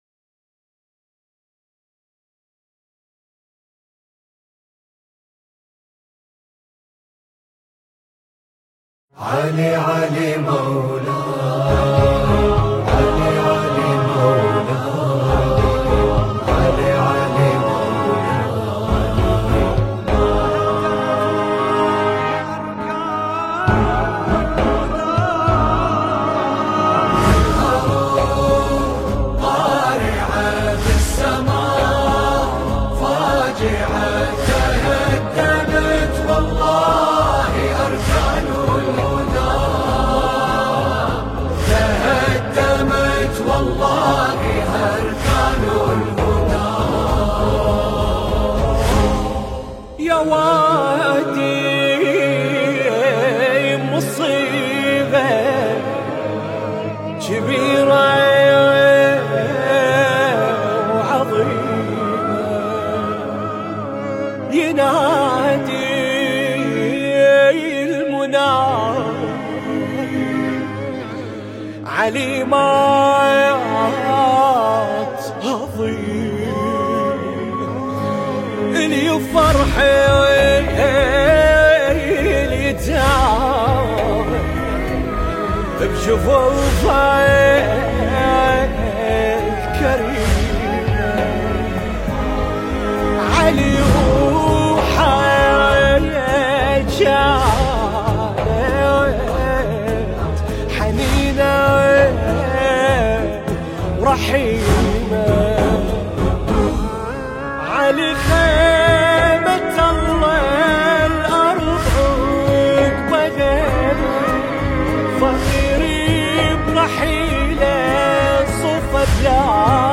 مكس وماستر